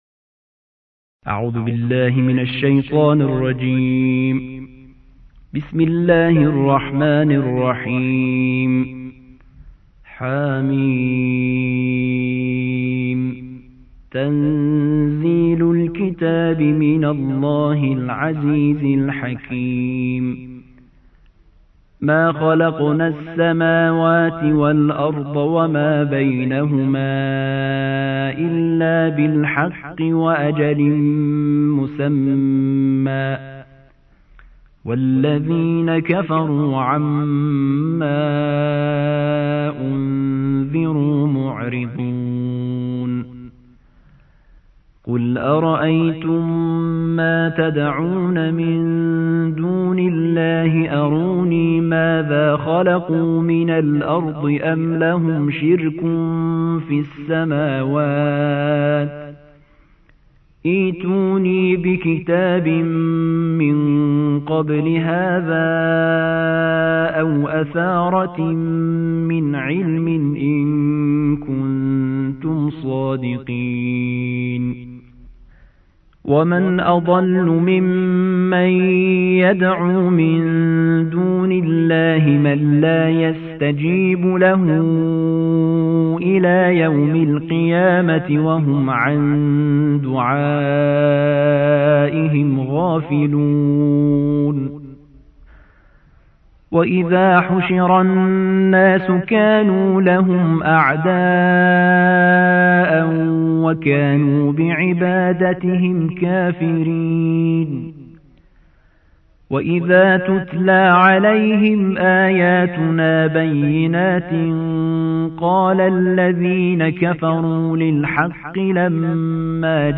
الجزء السادس والعشرون / القارئ